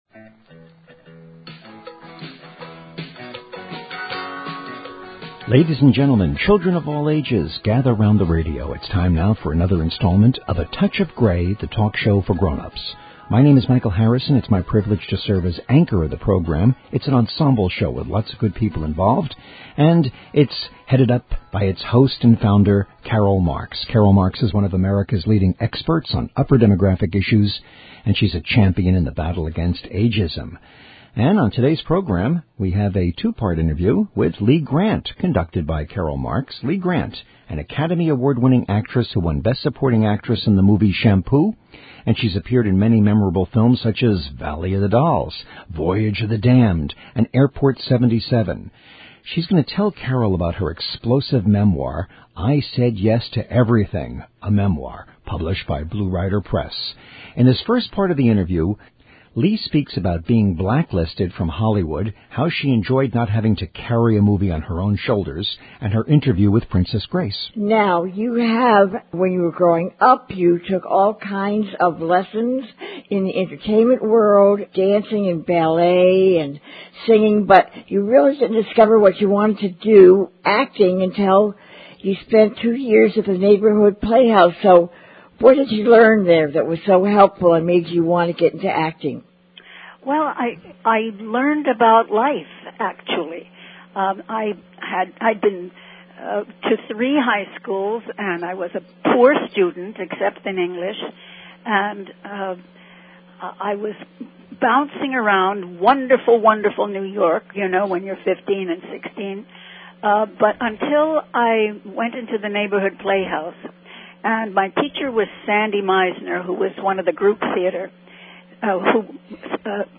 She will talk about her explosive memoir, I Said Yes To Everything: A Memoir.(Blue Rider Press) In this part of the interview, Lee speaks about her being blacklisted from Hollywood, how she enjoyed not having to carry a movie, and her interview with Princess Grace.